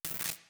SFX_Static_Electricity_Single_04.wav